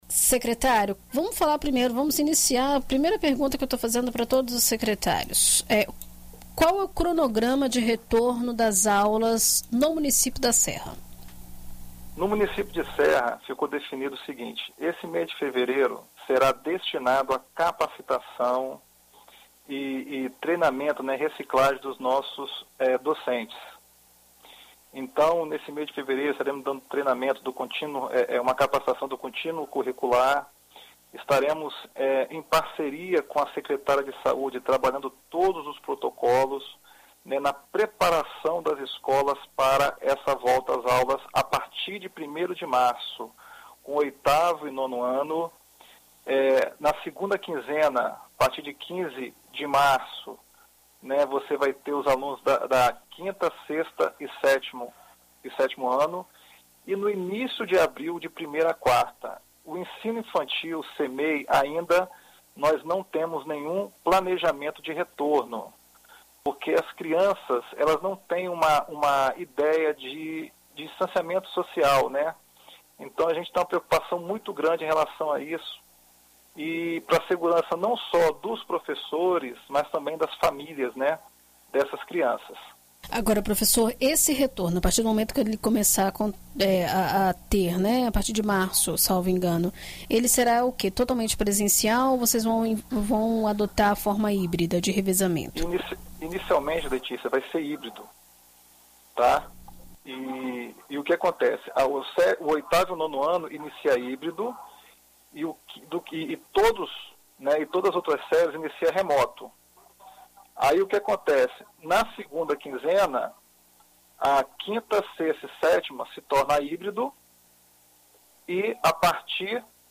Em entrevista à BandNews FM, o secretário Alessandro Bermudes detalha o plano de retorno às aulas e fala sobre a alta demanda, gerando uma fila de espera de 387 alunos
ENT-SECRETÁRIO-EDUCAÇÃO-SERRA.mp3